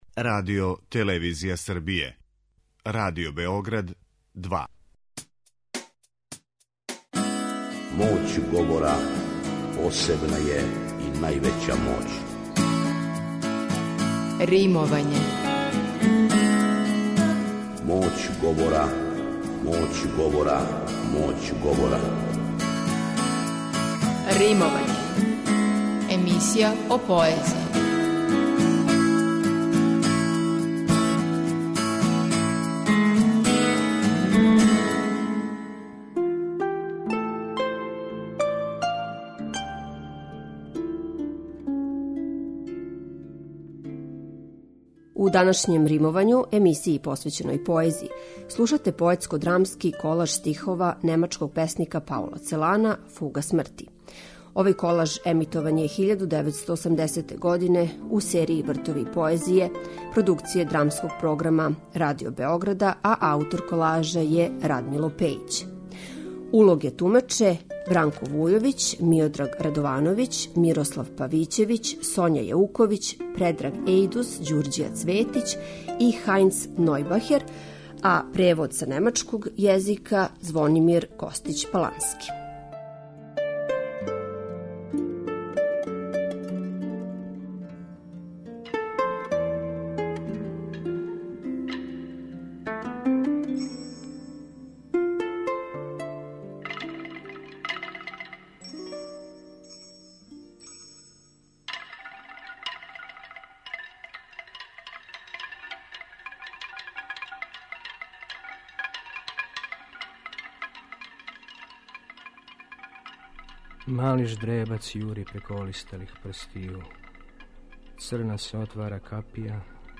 У данашњем 'Римовању', емисији посвећеној поезији, слушате 'Фугу смрти' - поетско-драмски колаж од стихова Паула Целана.
Овај колаж емитован је 1980. у серији "Вртови поезије", у продукцији Драмског прогама Радио Београда.